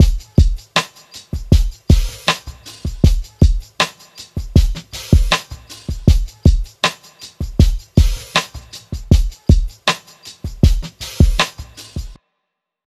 Cardiak_Drum_Loop_4_158bpm.wav